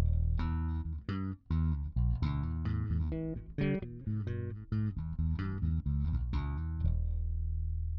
Quick recordings, rusty playing of 6 strings Harley Benton bass, passive mode.